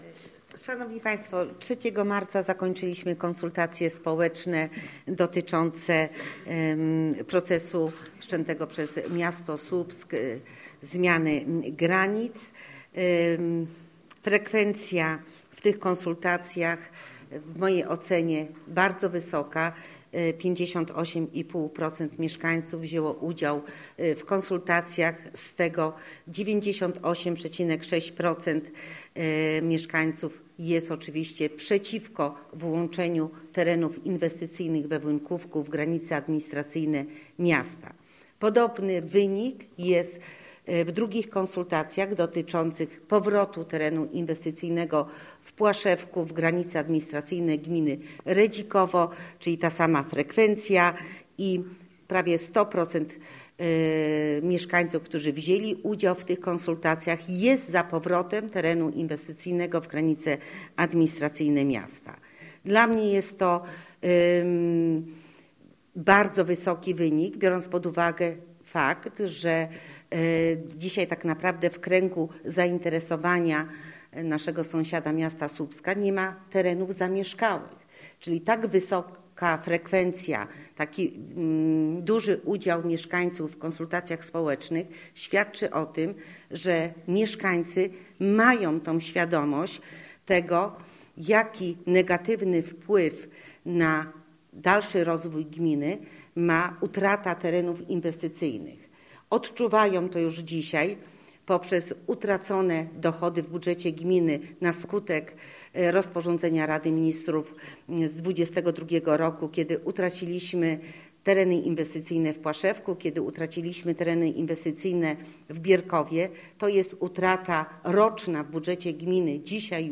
Posłuchaj rozmowy z Barbarą Dykier, wójt gminy Redzikowo: